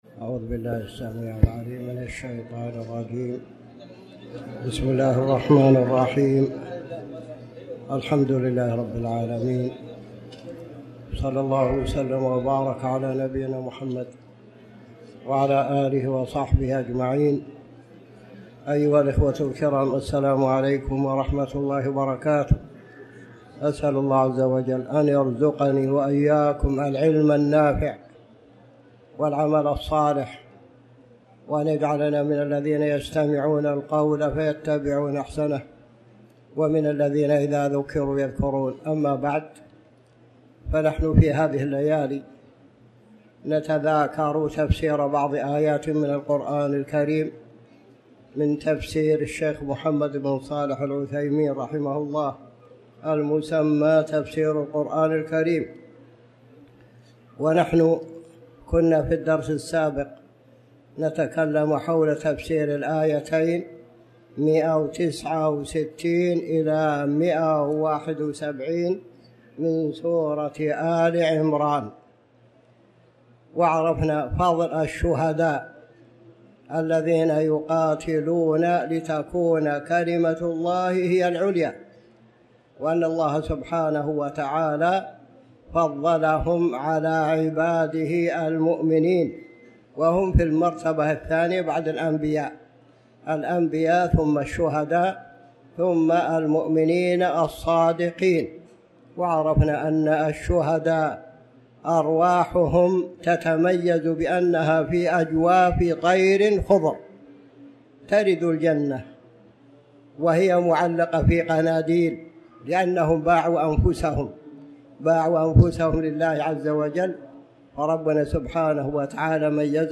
تاريخ النشر ٢٤ ربيع الأول ١٤٤٠ هـ المكان: المسجد الحرام الشيخ